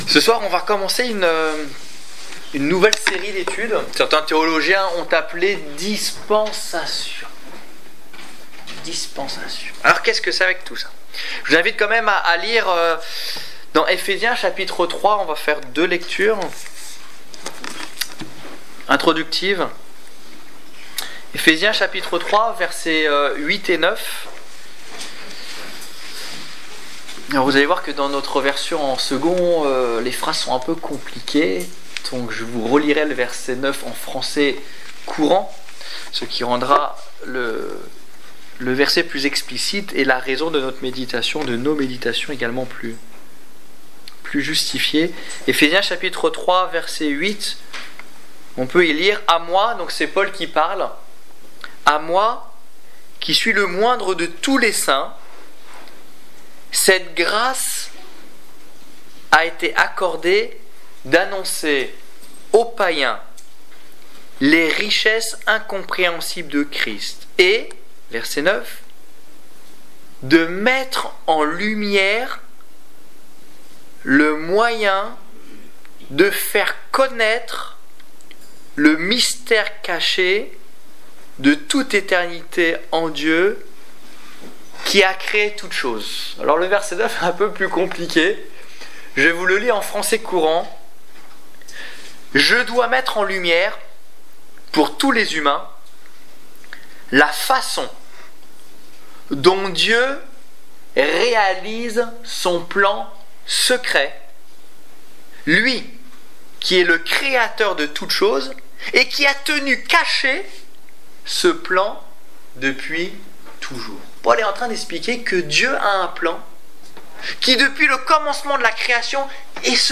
Étude biblique du 11 février 2015